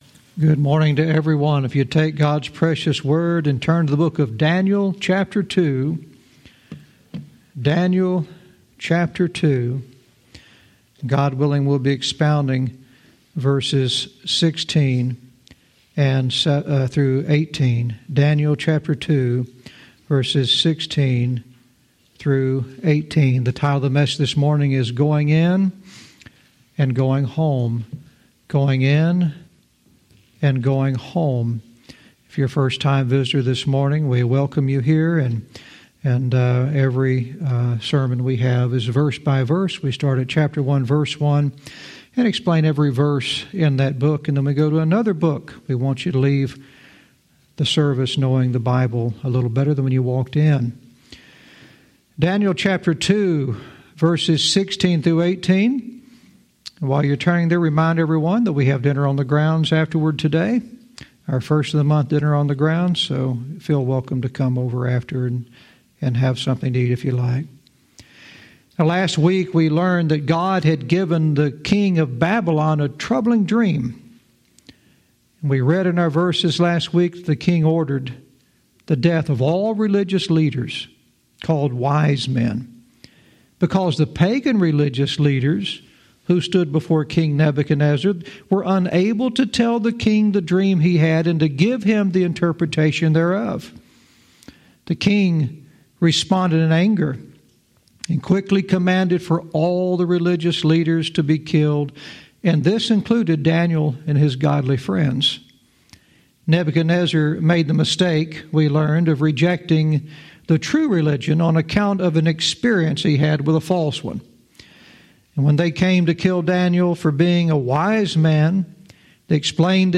Verse by verse teaching - Daniel 2:16-18 "Going In and Going Home"